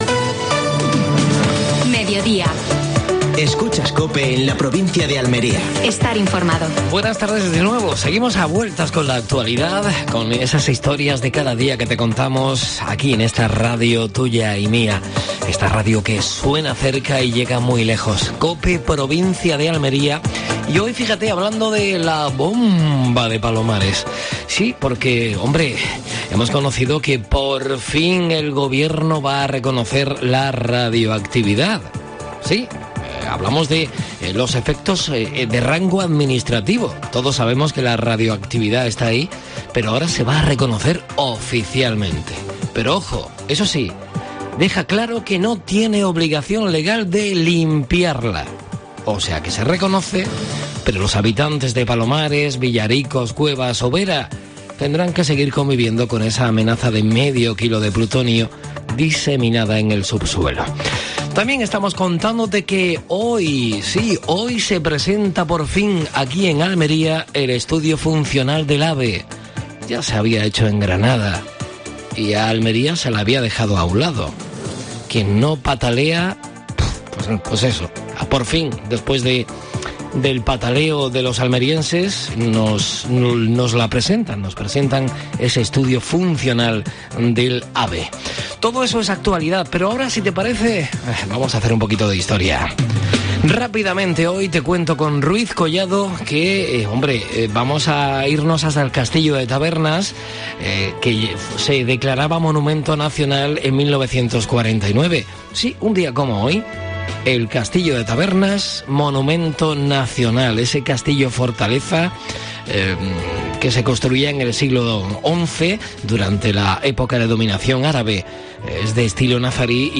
AUDIO: Actualidad en Almería. Entrevista a Manuel Abad (concejal del Ayuntamiento de Huércal de Almería). Última hora deportiva.